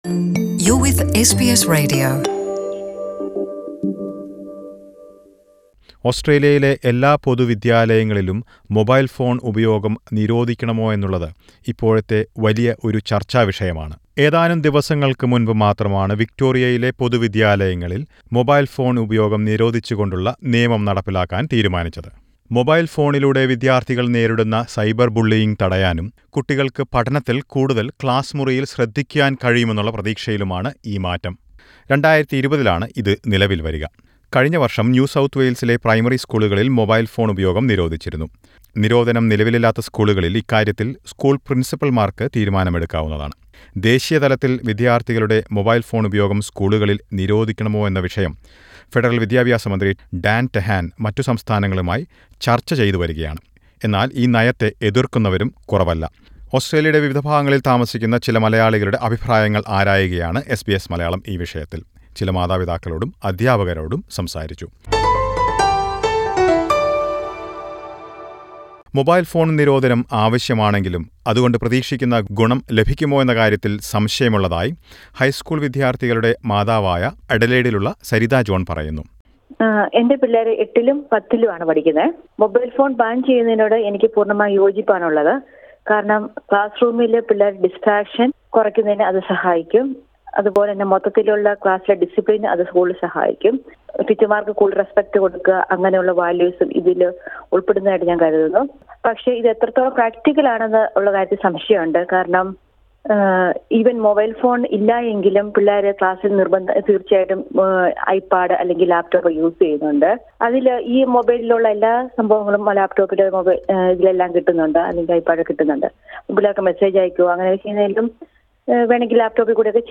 Is a nationwide ban needed is the big debate now. SBS Malayalam speaks to some Malayalee parents and teachers around Australia.